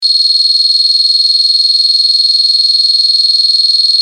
SIRENA 8 TONOS
Sirena con 8 tonos diferentes
Tono_2